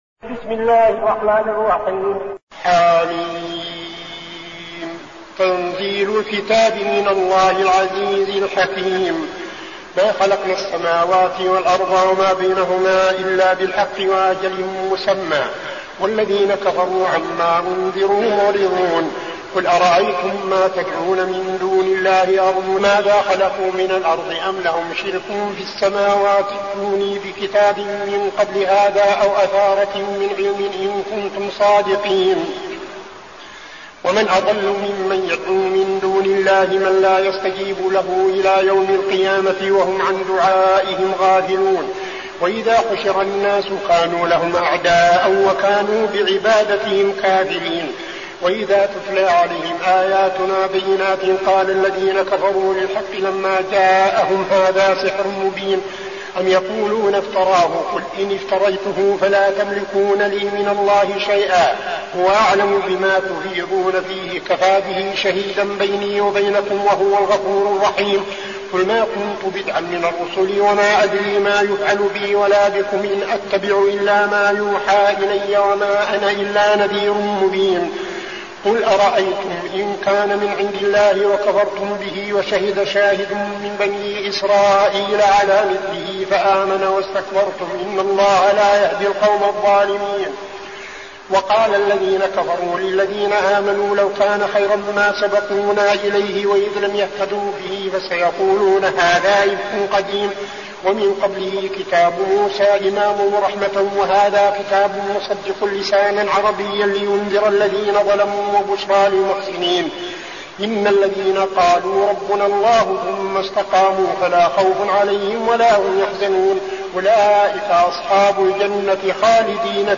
المكان: المسجد النبوي الشيخ: فضيلة الشيخ عبدالعزيز بن صالح فضيلة الشيخ عبدالعزيز بن صالح الأحقاف